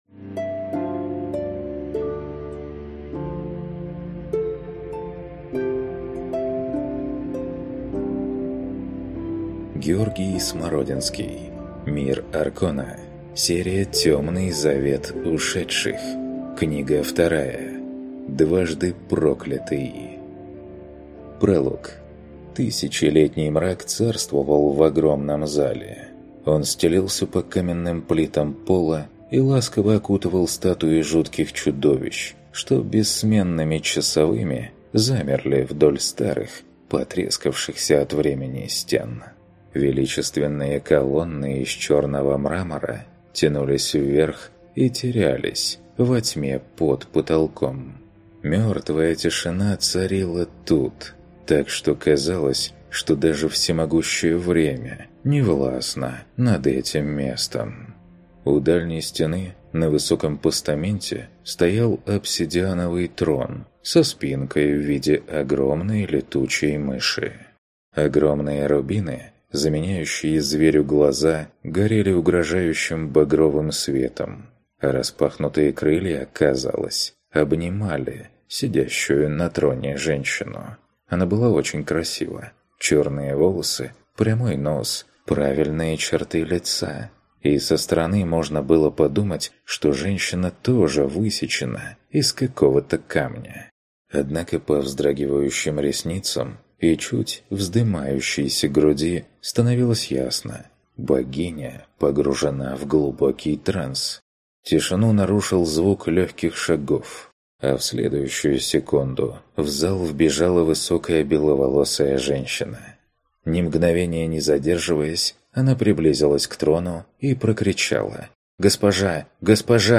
Аудиокнига Дважды проклятый | Библиотека аудиокниг